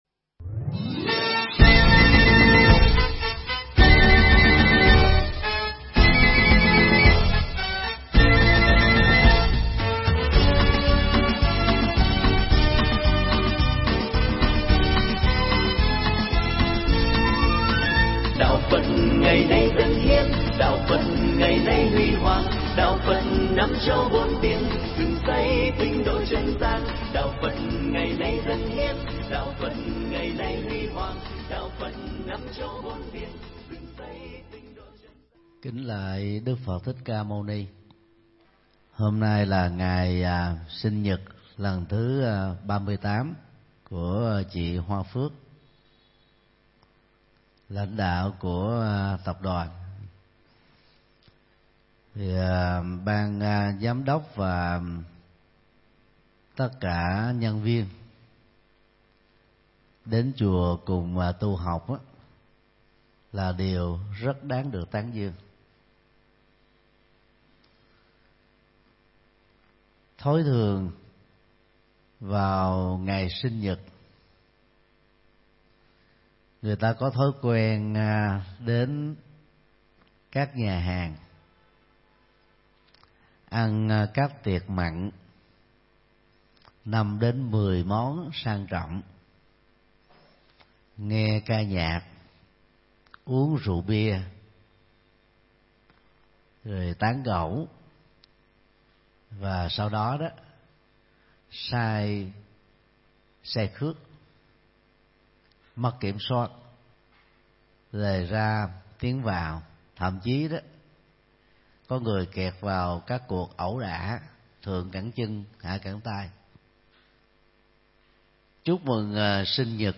Mp3 Thuyết Pháp Cách Tổ Chức Sinh Nhật Hạnh Phúc Và Giải Quyết Vấn Nạn – Thượng Tọa Thích Nhật Từ giảng tại chùa Giác Ngộ, ngày 10 tháng 9 năm 2018